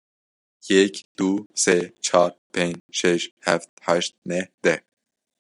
Pronounced as (IPA) /seː/